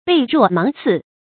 背若芒刺 bèi ruò máng cì
背若芒刺发音